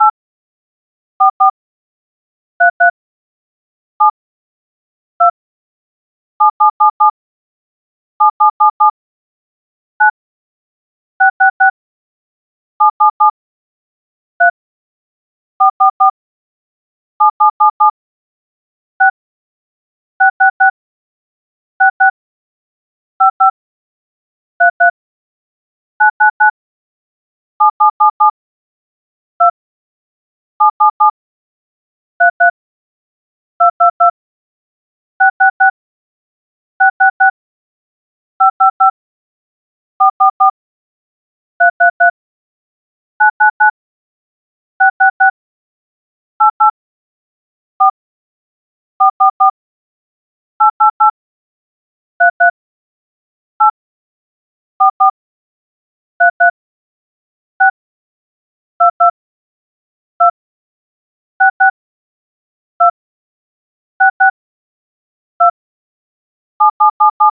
Seems to be the sound made by a phone when you're dialing.
So now we have an audio of DTMF tones that we want to decode (we want to get the key that was pressed from its sound).